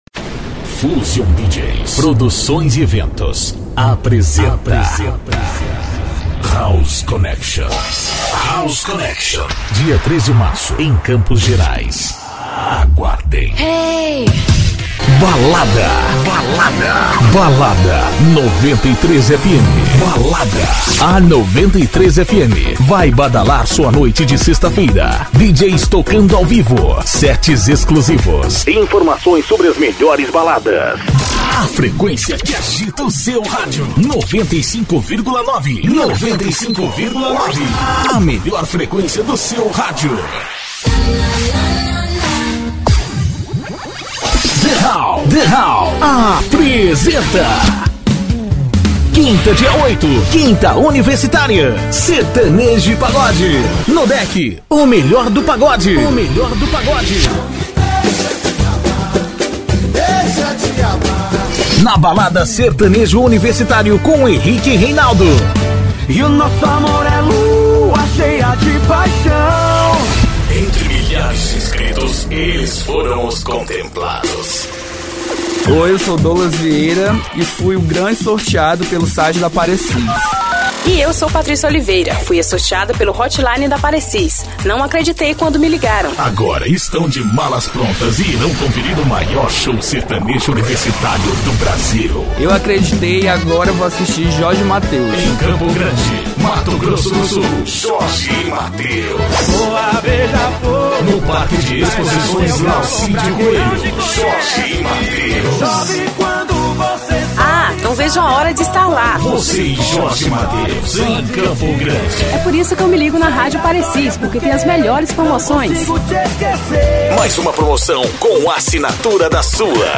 EstiloLocutores